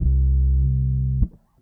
808s
bass2.wav